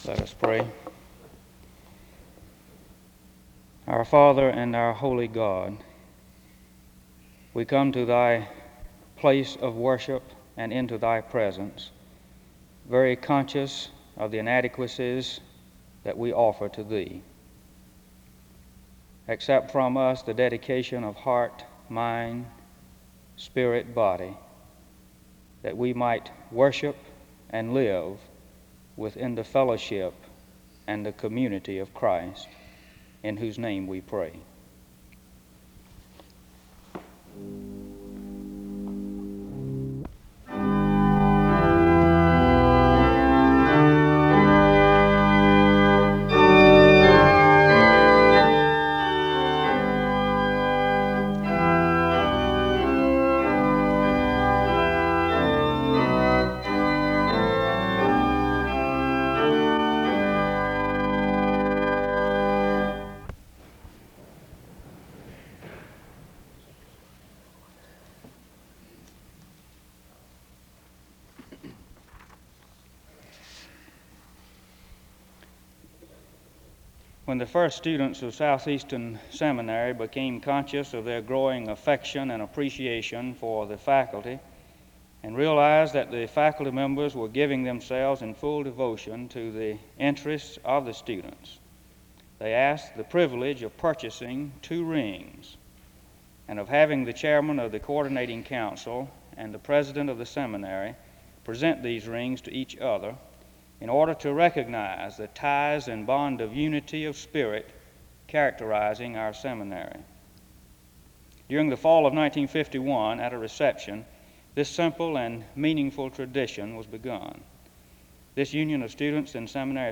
The service begins with a word of prayer and music from 0:00-1:02. There is a presentation of rings to symbolize the positive relationships with the faculty and the student body from 1:16-4:37. A prayer is offered from 4:58-7:37. Music is played from 7:39-8:14.
SEBTS Chapel and Special Event Recordings SEBTS Chapel and Special Event Recordings